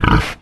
boar_threaten_0.ogg